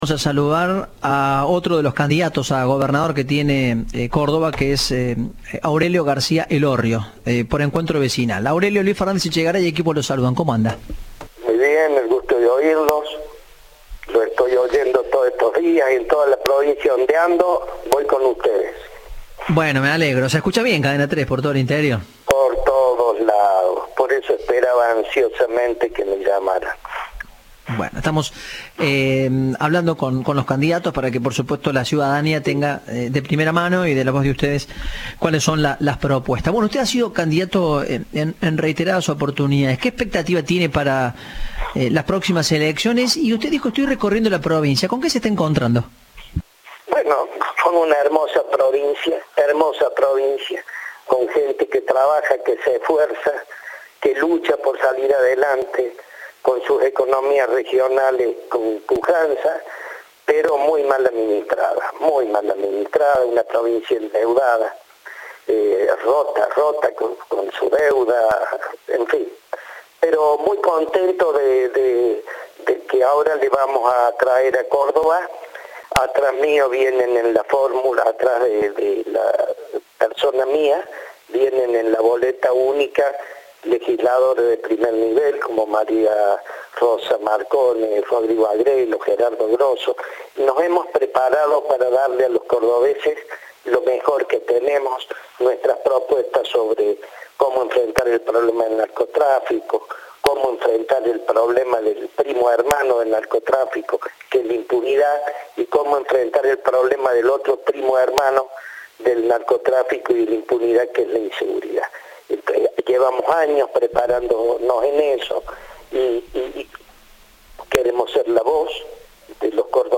El candidato a gobernador de Córdoba por Encuentro Vecinal habló con Cadena 3 de sus planes para luchar contra la inseguridad. Expresó preocupación por la tasa de aborto legal en la provincia.